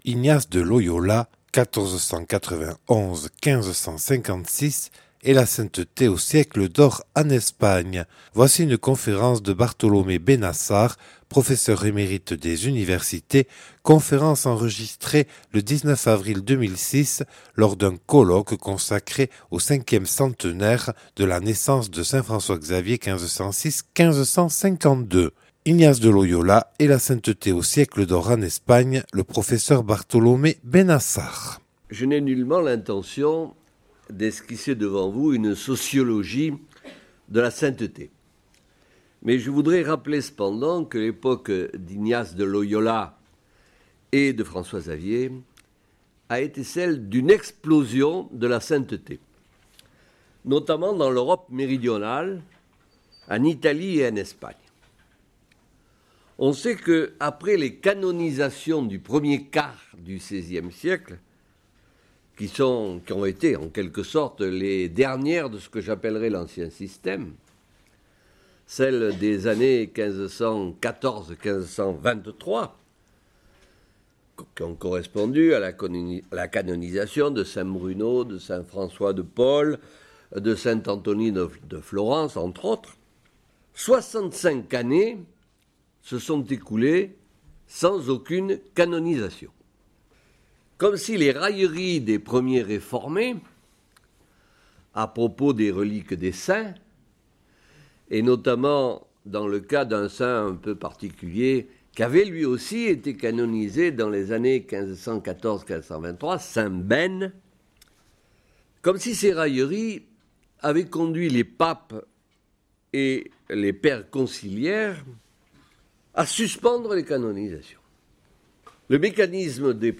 Conférence de Bartolomé Benassar, professeur d’université.
Enregistrée le 19/04/2006 à Bayonne lors du colloque du 5ème centenaire de la naissance de St François-Xavier (1506-1552)